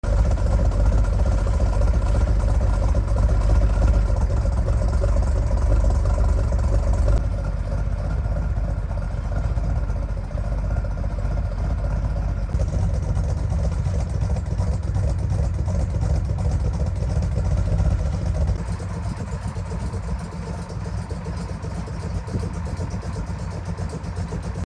Just listen to the 1969 sound effects free download
Just listen to the 1969 Ford Mustang Mach 1 R-code 428 Cobra-Jet Ram Air V8 Fastback